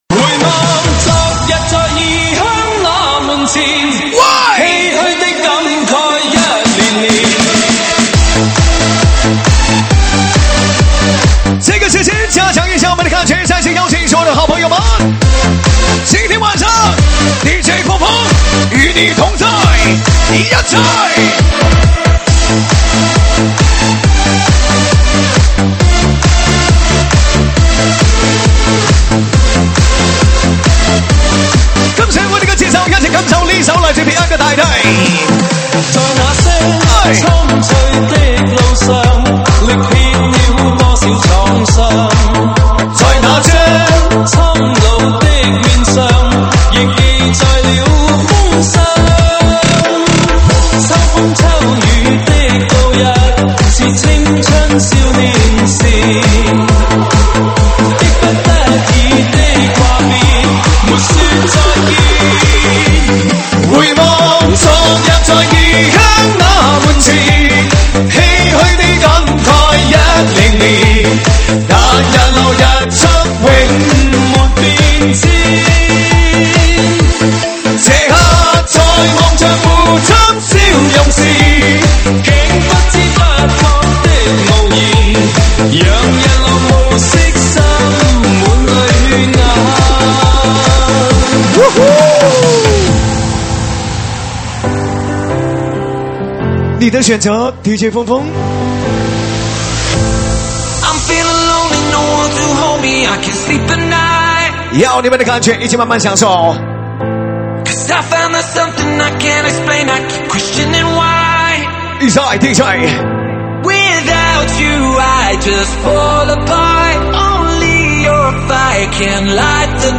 舞曲类别：周榜单